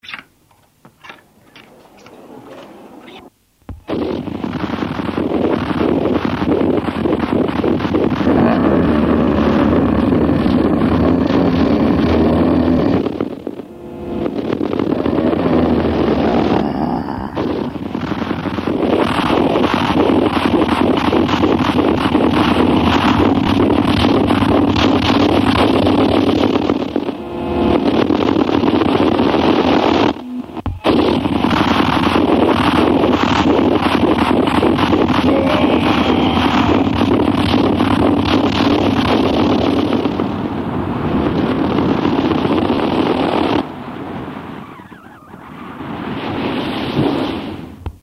File under: Industrial / Noise